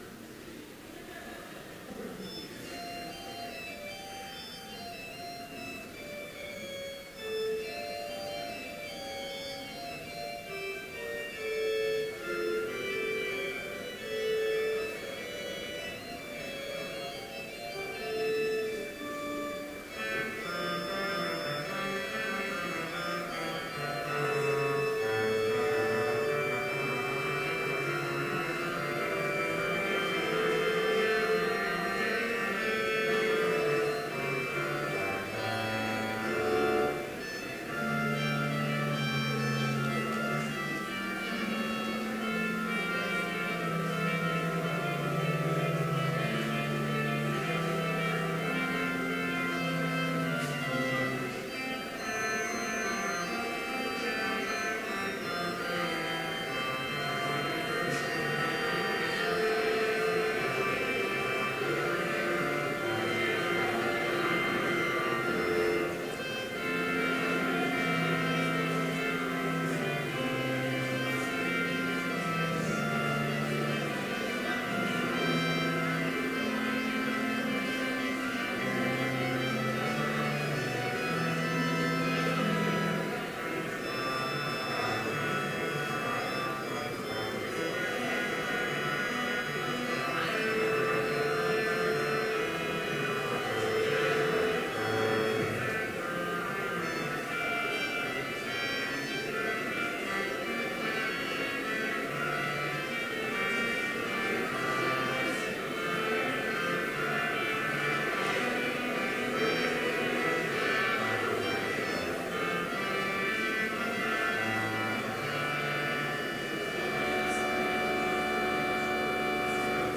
Complete service audio for Chapel - February 5, 2015
Listen Complete Service Audio file: Complete Service Sermon Only Audio file: Sermon Only Order of Service Prelude Hymn 6, vv. 1-3, Alleluia! Let Praises Ring Reading: Romans 5:1-2 Homily Prayer Hymn 6, v. 4, Alleluia!
Postlude